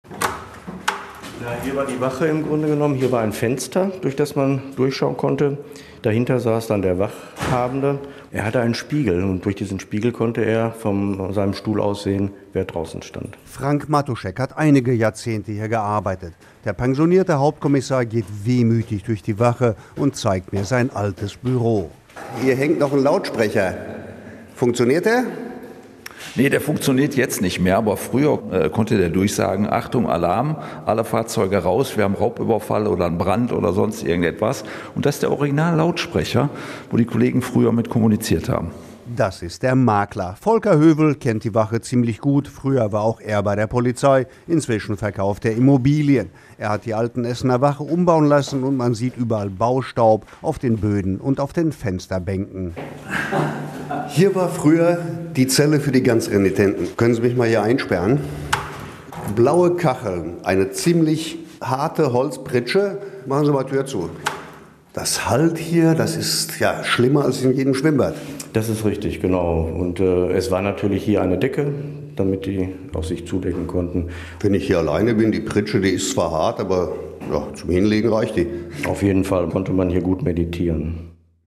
rundgang-durch-die-ehemalige-polizeiwache.mp3